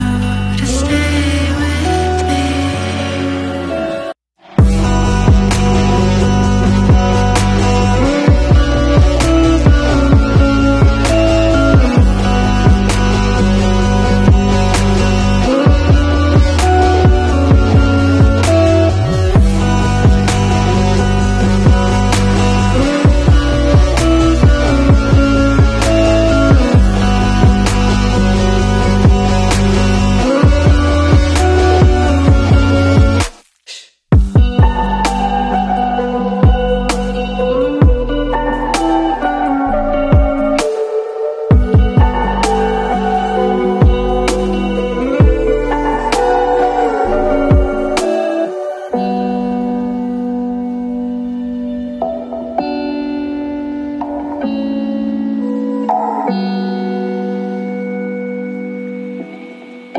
Mini Tour of my room with my song “100 Moons” in the background🥰🌒🌑